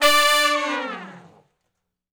014 Long Falloff (D) unison.wav